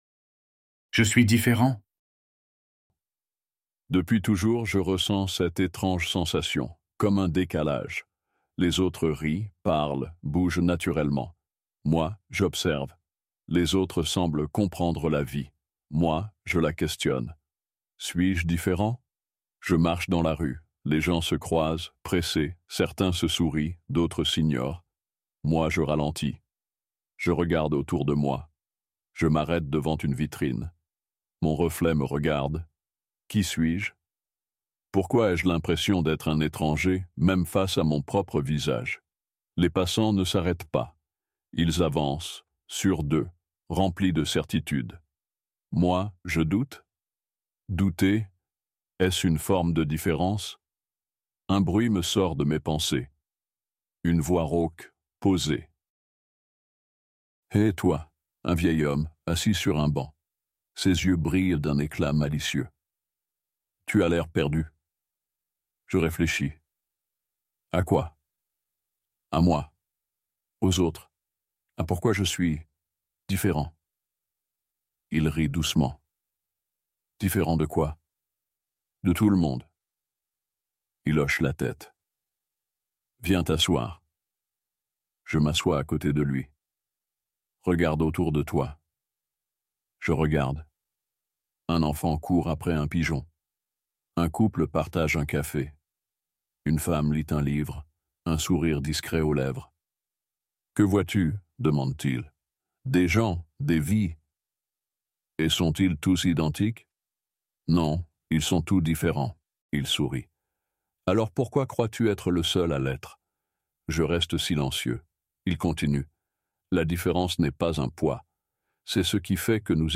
Apprenez le français avec un dialogue pratique + PDF.